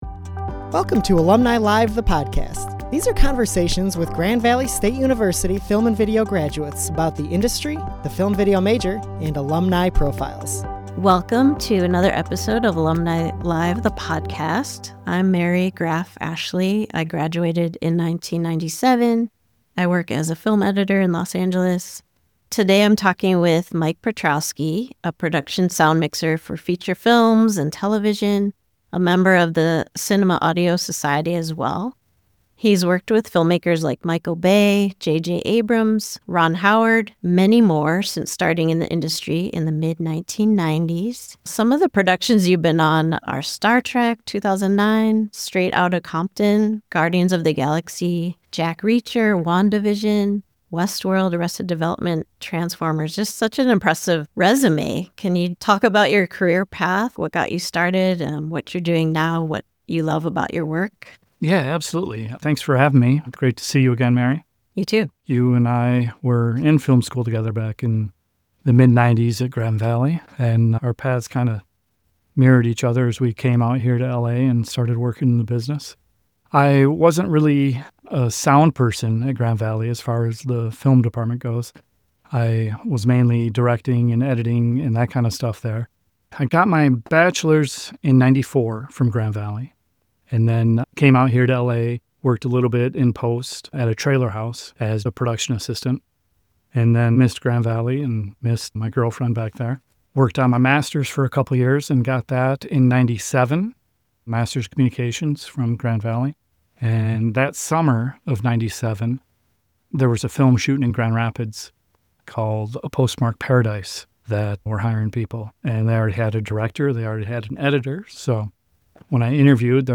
Production Sound Mixing: An Interview